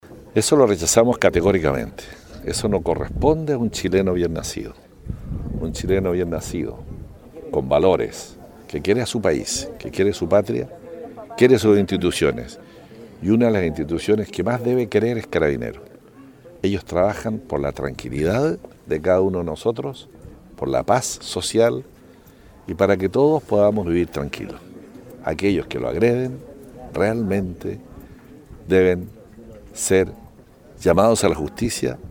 La escuela de formación policial de Ancud realizó la ceremonia de graduación de 145 nuevos Carabineros en la jornada del domingo 01 de diciembre.
En el contexto de la actual crisis social en el país, el intendente de Los Lagos repudió las agresiones y ataques recibidos por parte de personas que de esta manera le faltan el respeto al país, dijo la autoridad.